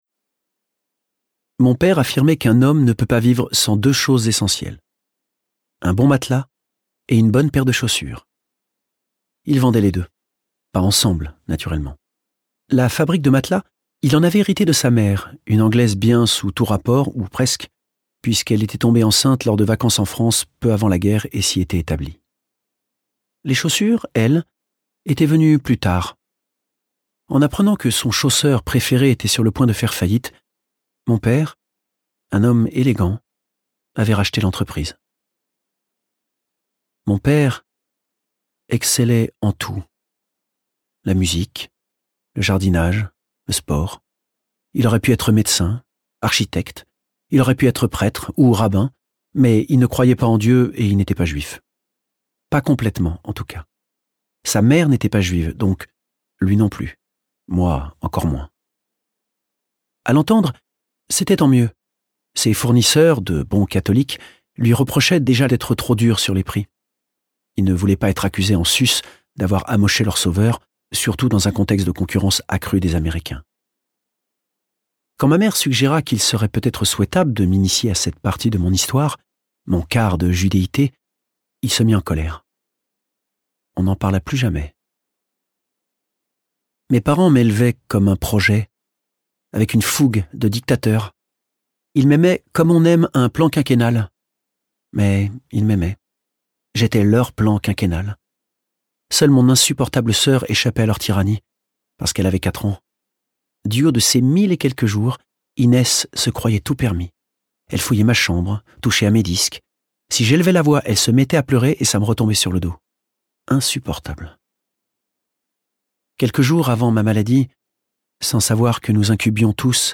Extrait gratuit - Des diables et des saints de Jean-Baptiste Andrea